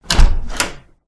DoorOpen3.wav